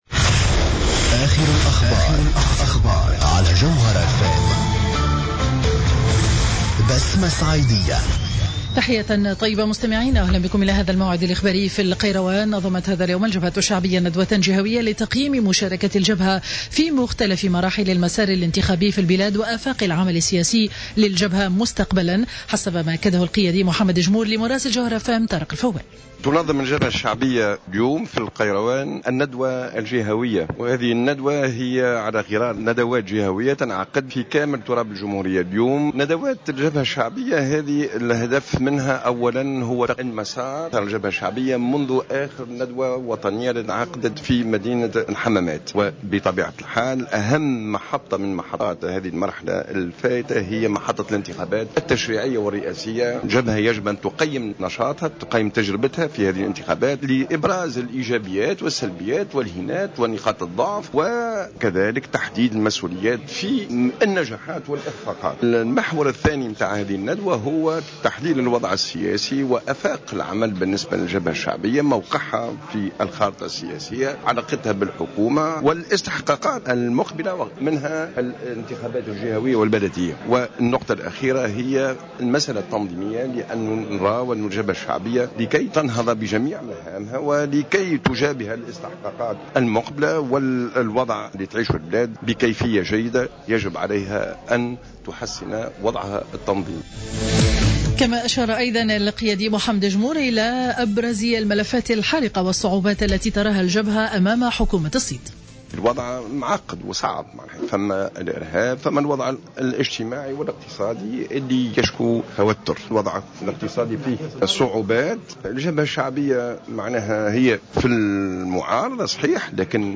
نشرة أخبار منتصف النهار ليوم الأحد 10 ماي 2015